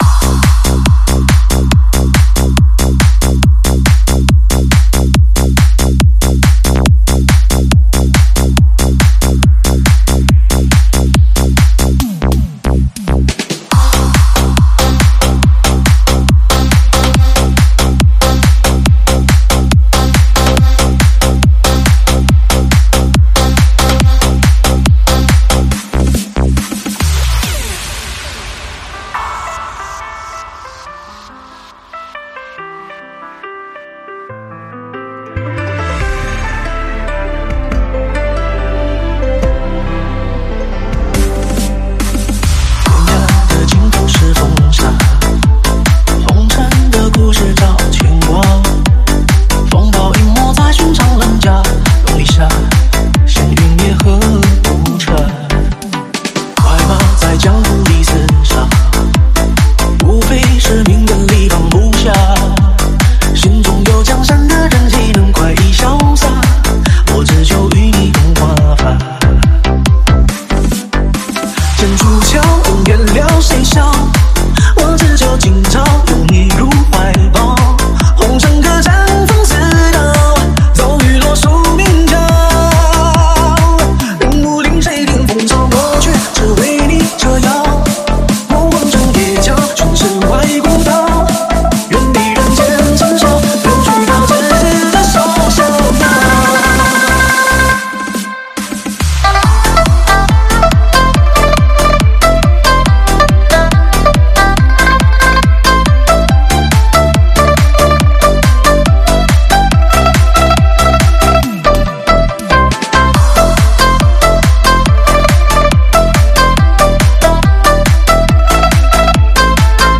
试听文件为低音质